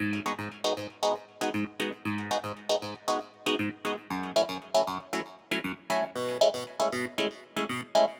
04 Clavinet PT4.wav